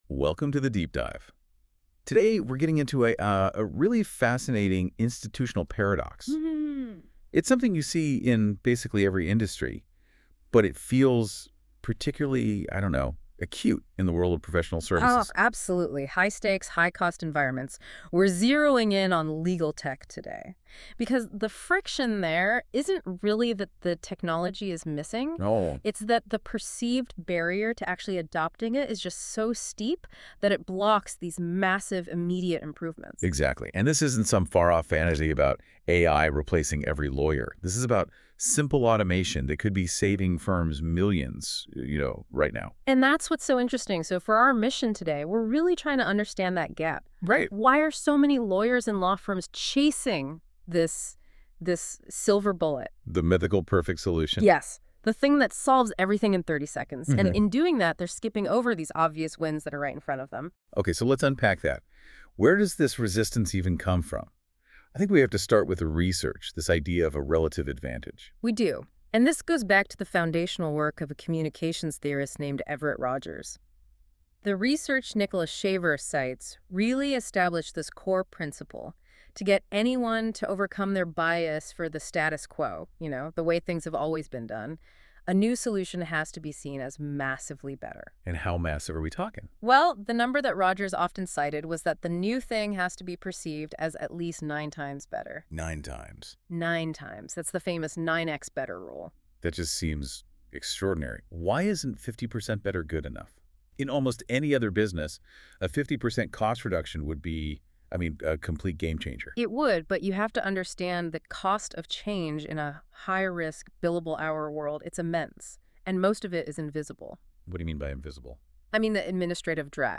Google Notebook LM - Does New Legal Tech Really Need to Be 9x Better to Succeed.m4a